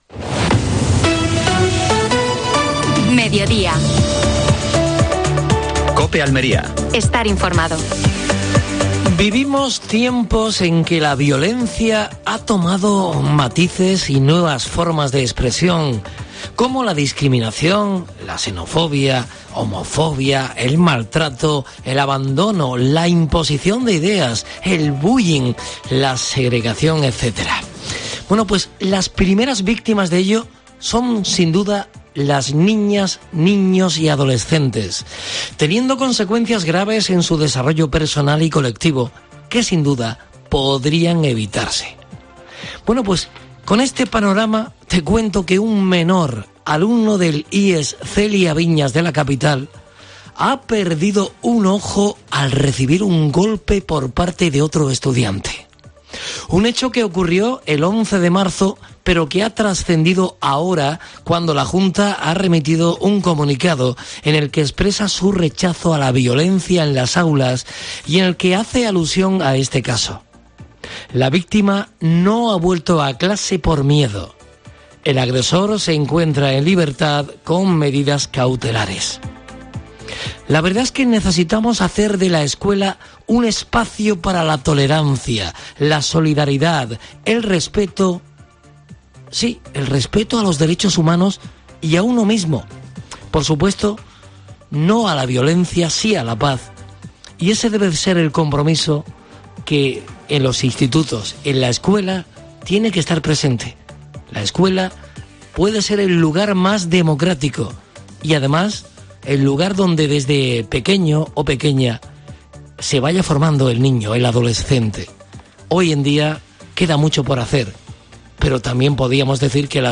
AUDIO: Actualidad, deportes, entrevista al vicepresidente de la Diputación de Almería, Fernando Giménez, en Salón Gourmets.